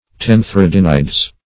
Search Result for " tenthredinides" : The Collaborative International Dictionary of English v.0.48: Tenthredinides \Ten`thre*din"i*des\, n. pl.